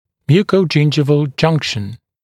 [ˌmjuːkəuˈdʒɪndʒɪvl ‘ʤʌŋkʃn] [ˌмйу:коуˈджиндживл ‘джанкшн] слизисто-десневое соединение; переходная складка десны